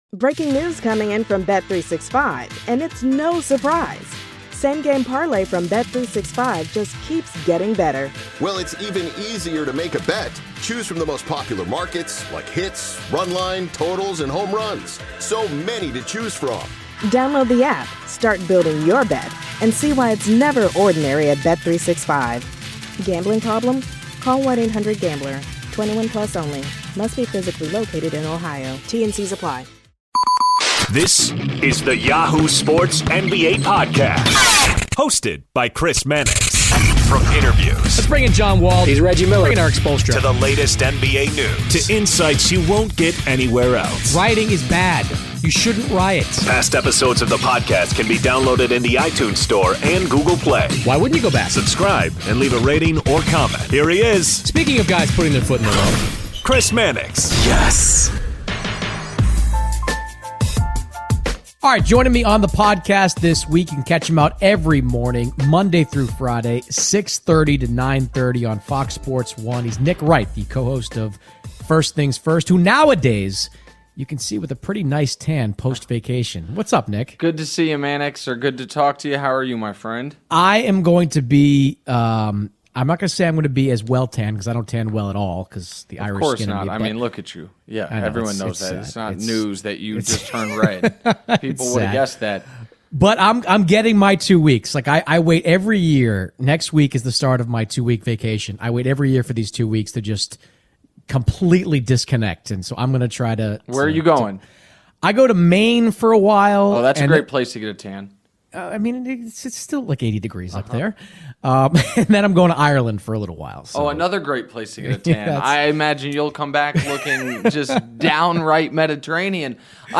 FS1's Nick Wright The Crossover NBA Show SI NBA Basketball, Sports 4.6 • 641 Ratings 🗓 14 August 2018 ⏱ 50 minutes 🔗 Recording | iTunes | RSS 🧾 Download transcript Summary Joining Chris Mannix of Yahoo Sports this week is Nick Wright, the co-host of First Things First on FS1.